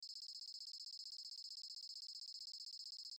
Электронный звук автоматической прокрутки базы данных на экране